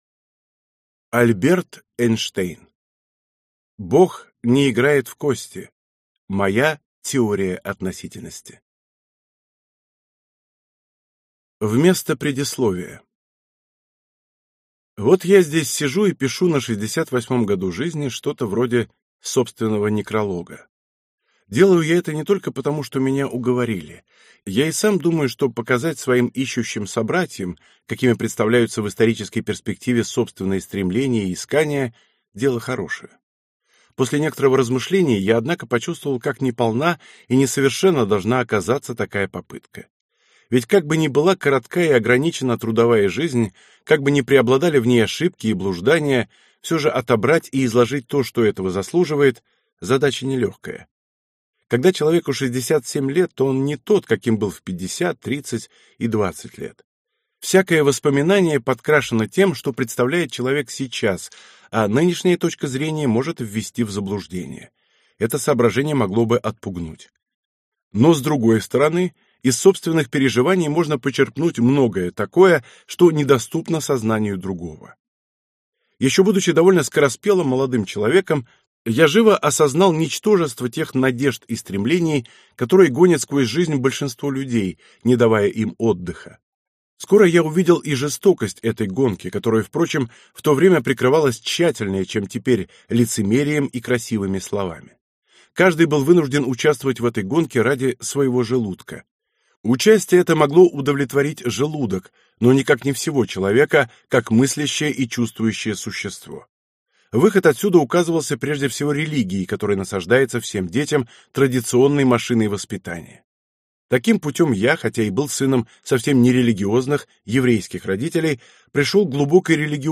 Аудиокнига Бог не играет в кости. Моя теория относительности | Библиотека аудиокниг